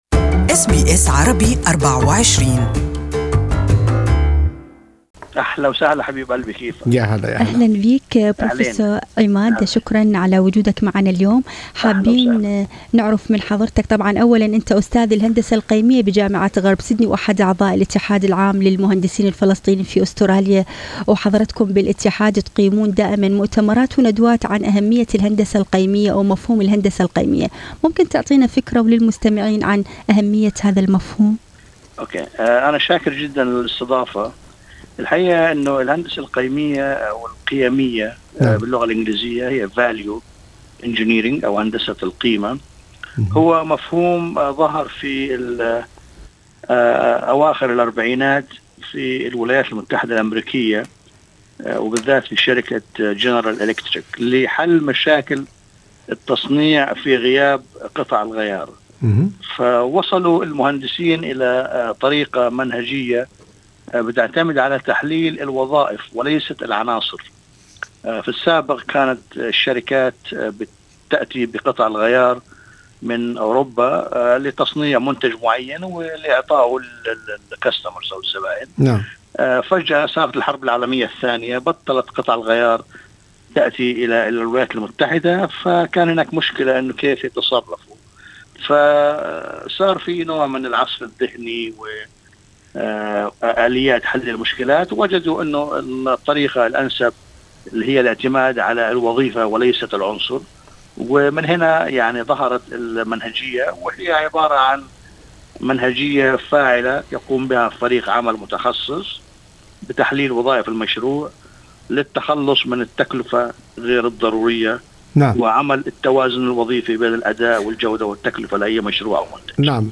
لقاءِ مباشر